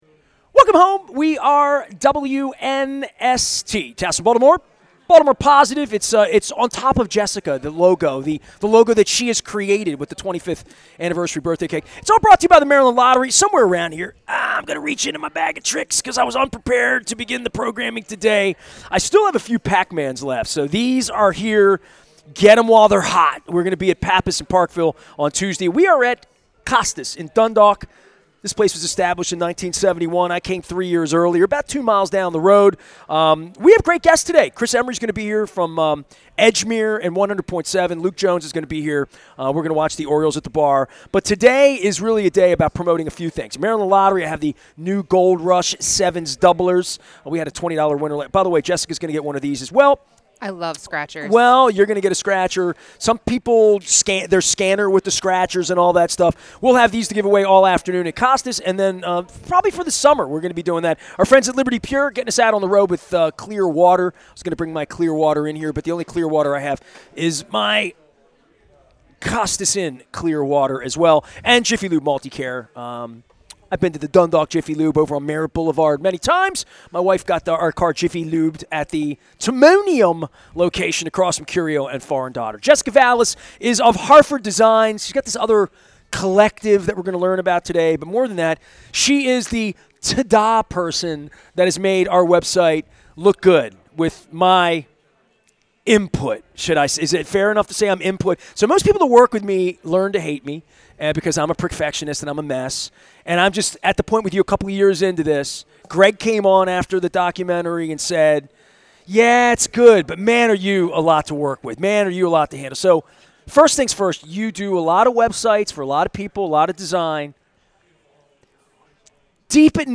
at Costas Inn on the Maryland Crab Cake Tour for a discussion on local business and making it work on the web